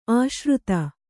♪ āśruta